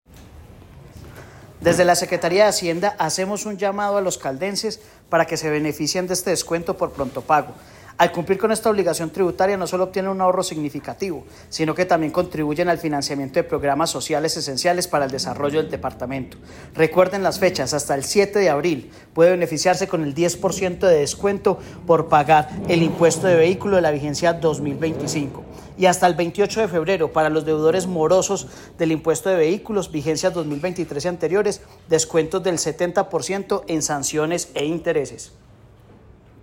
Secretario de Hacienda de Caldas, John Alexander Alzate Quiceno.